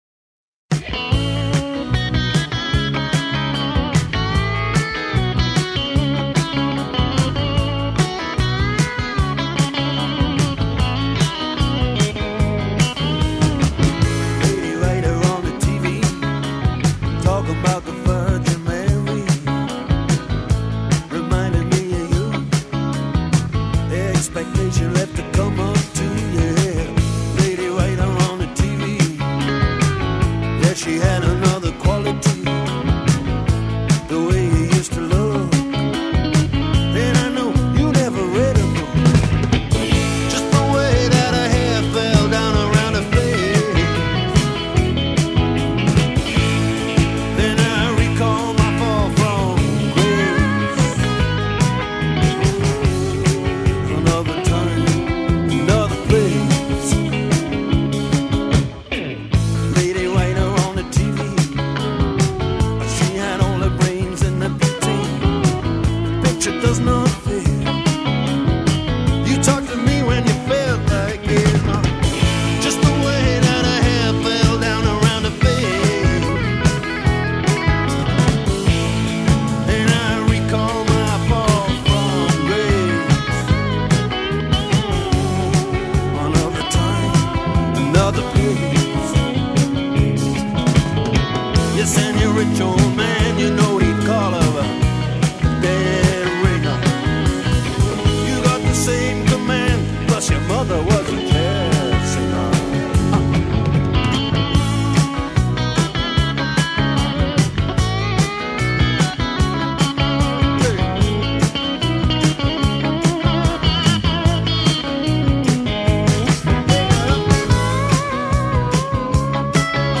The guitar work on it is phenomenal.